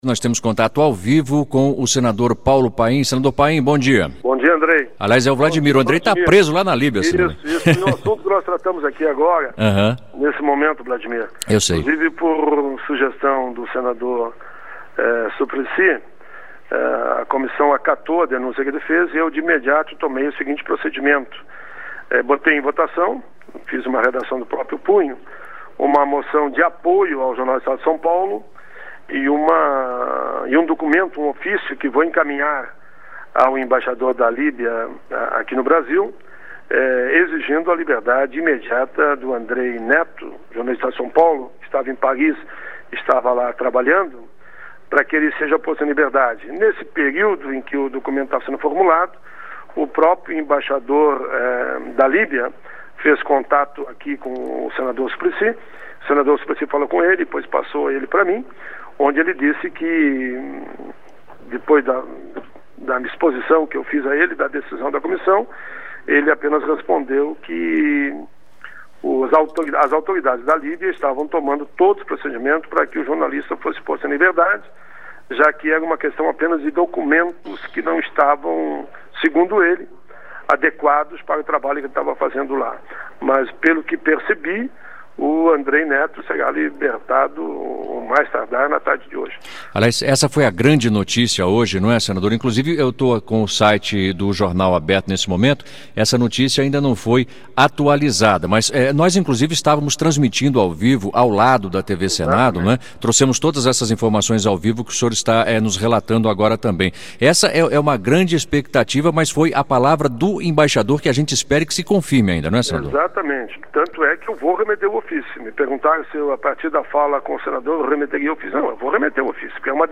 CDH aprova duas moções contra prisão de repórter na Líbia Entrevista com o senador Paulo Paim (PT-RS), presidente da Comissão de Direitos Humanos e Legislação Participativa.